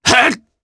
Ezekiel-Vox_Attack1_jp_b.wav